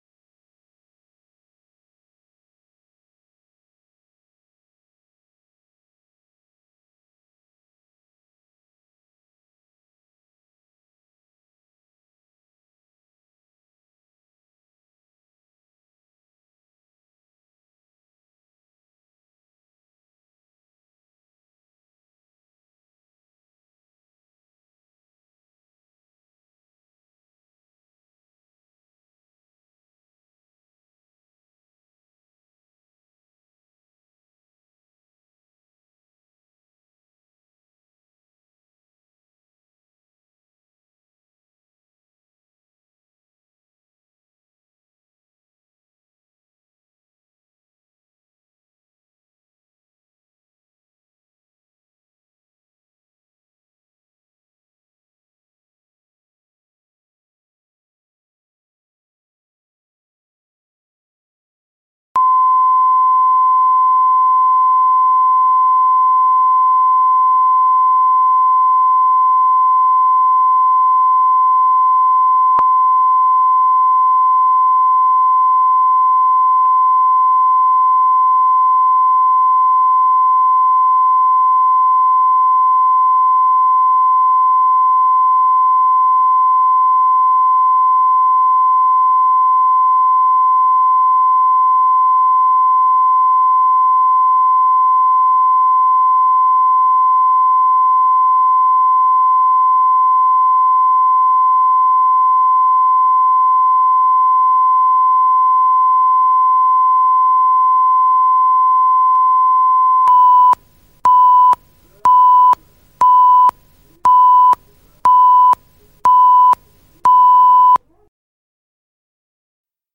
Аудиокнига Серебряная свадьба